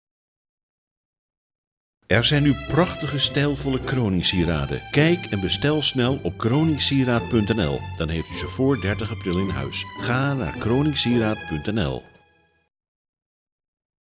Luister hier naar de radiocommercial.